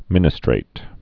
(mĭnĭ-strāt)